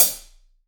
AMB CLHH2.wav